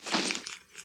equip_diamond4.ogg